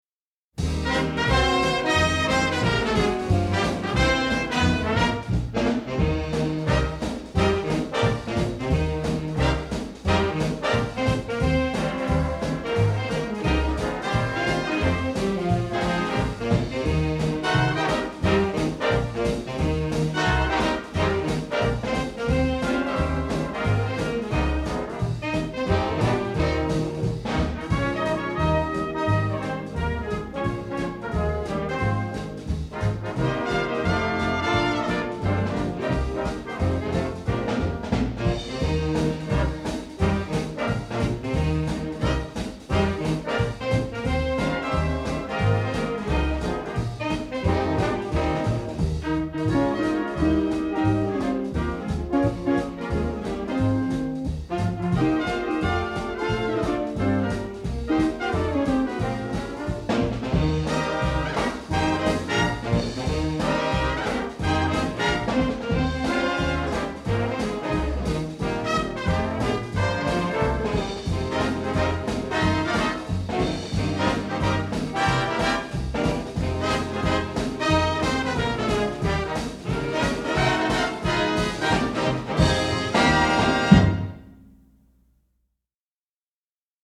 The first line sounds like another big band WWII song (Tuxedo Junction) but it’s not that because from line two on it goes completely elsewhere yet sounds very familiar.
mystery-song.mp3